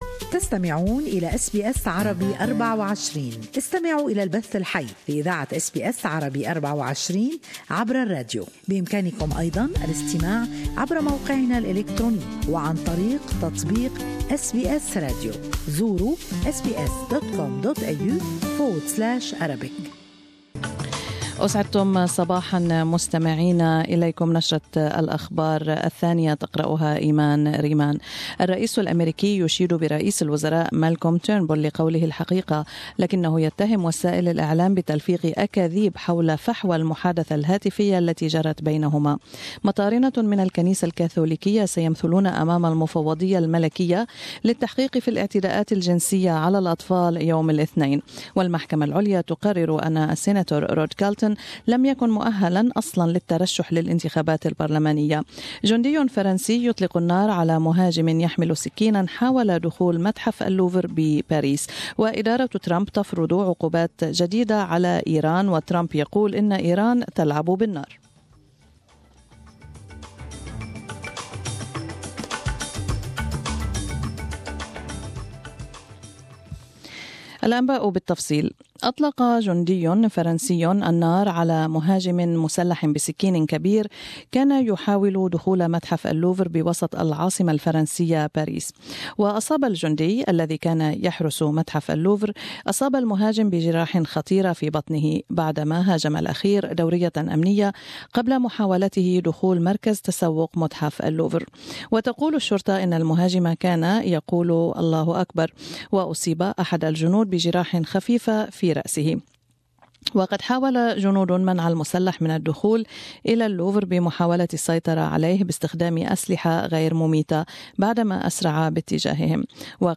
News Bulletin 4 February 2017